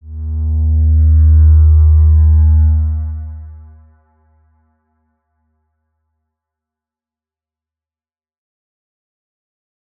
X_Windwistle-D#1-mf.wav